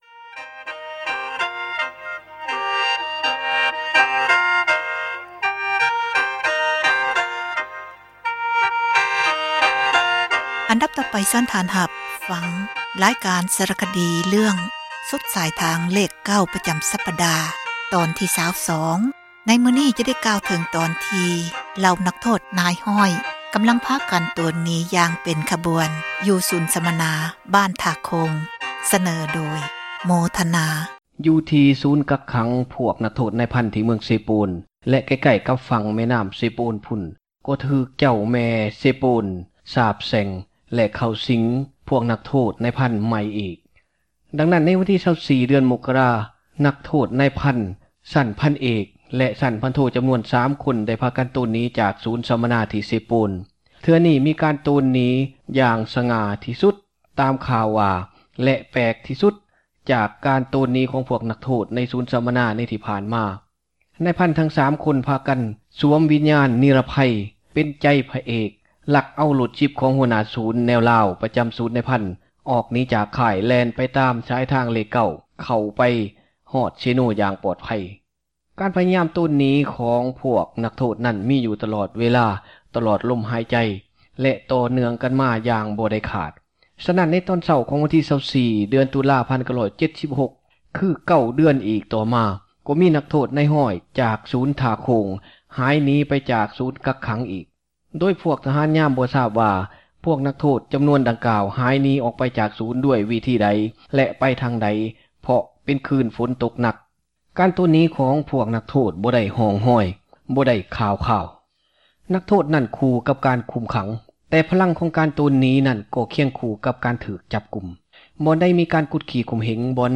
ສາຣະຄະດີ ເຣື້ອງ ສຸດສາຍທາງເລຂ 9 ຕອນທີ 22 ຈະໄດ້ ກ່າວເຖິງ ບັນດາ ນັກໂທດ ນາຍຮ້ອຍ ພາກັນ ໂຕນໜີ ຢ່າງ ເປັນຂະບວນ ທີ່ ສູນສັມມະນາ  ບ້ານ ທ່າໂຄ່ງ. ສເນີໂດຍ